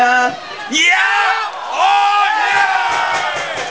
Bei den aufgenommenen Torsequenzen fallen im Hintergrund immer wieder irgendwelche komischen Kommentare, man hört Jubelschreie oder wilde Diskussionen...